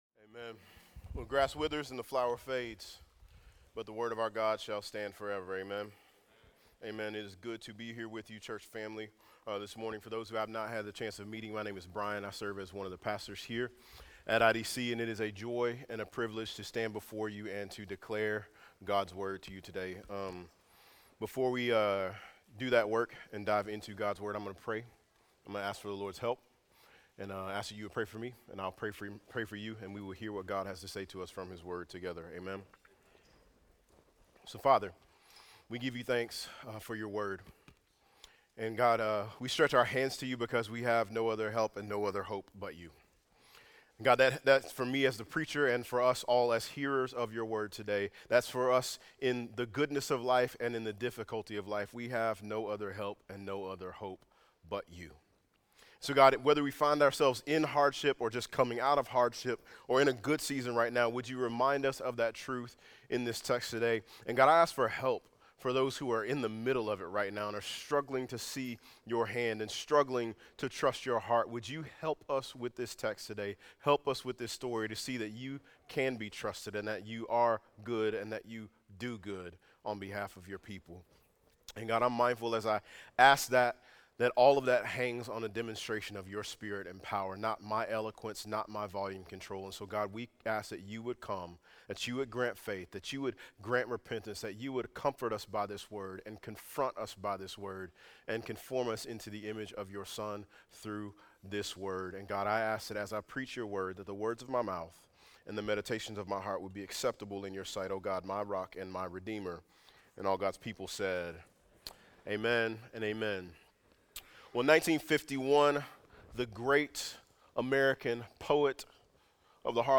Genesis 20:1-18 View this week’s sermon outline Questions for reflection and discussion